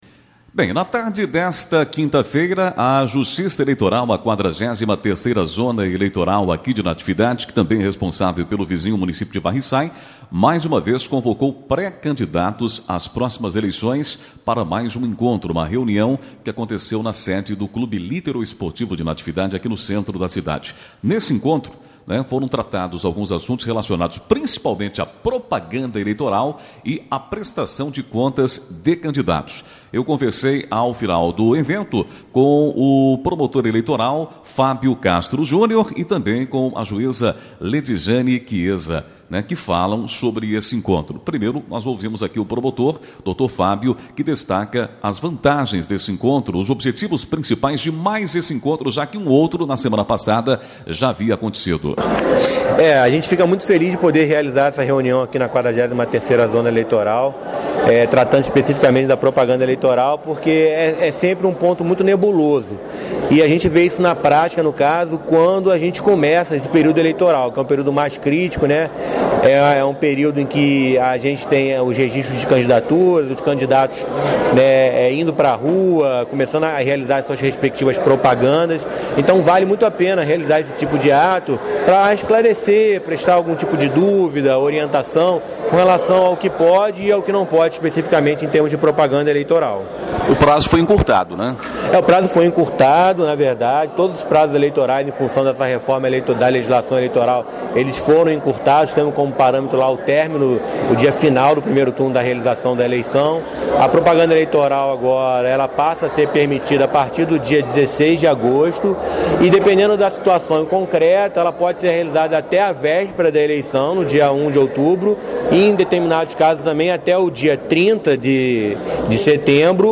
Durante o encontro, na tarde desta última quinta-feira (21), na sede social do CLEN, Centro de Natividade, servidores do cartório local, além do promotor Fábio Castro Júnior e a juíza Leidejane Chieza, orientaram os postulantes sobre as novas regras impostas pelo TSE, sobre propaganda eleitoral e prestação de contas. Ambos falaram à Rádio Natividade.